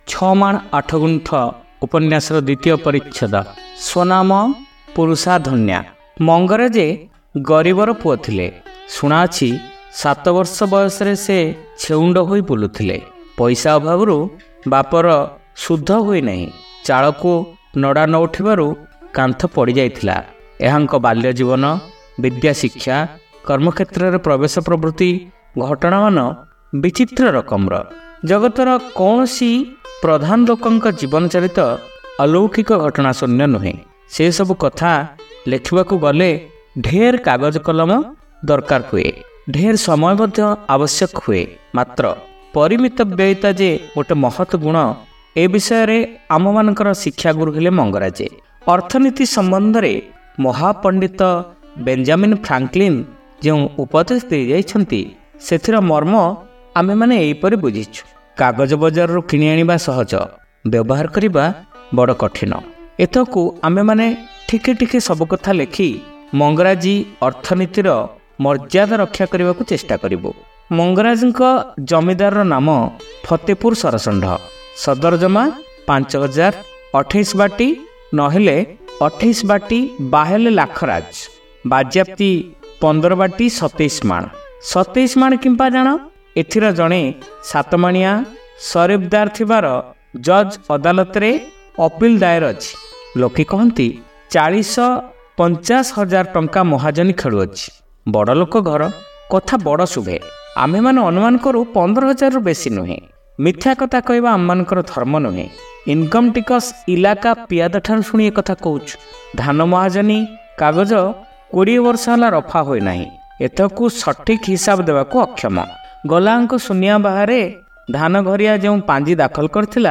ଶ୍ରାବ୍ୟ ଉପନ୍ୟାସ : ଛମାଣ ଆଠଗୁଣ୍ଠ (ଦ୍ୱିତୀୟ ଭାଗ)